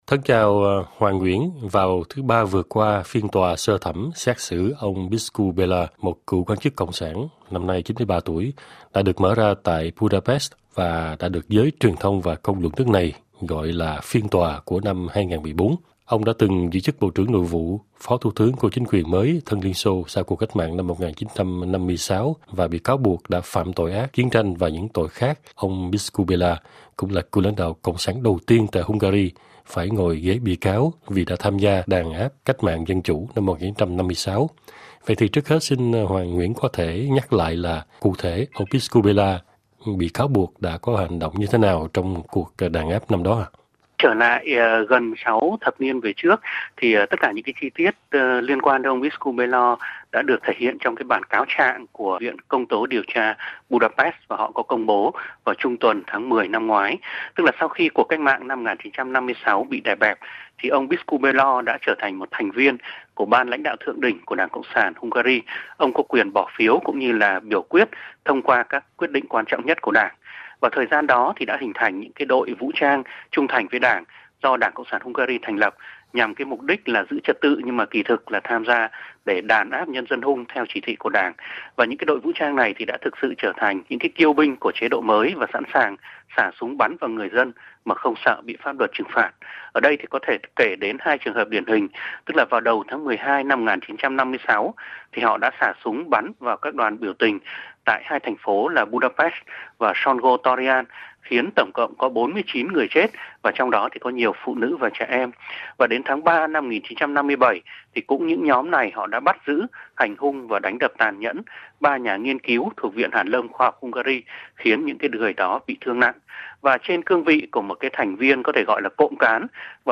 Thông tín viên